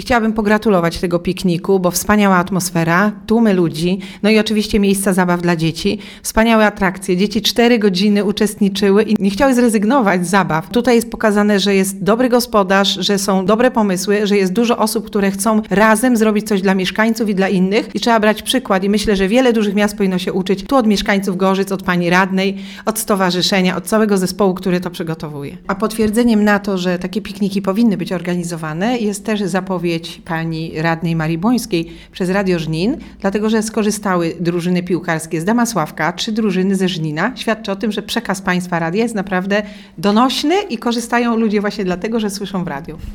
Wiceburmistrz pochwaliła organizację imprezy.
2014-festyn-nowakowska.mp3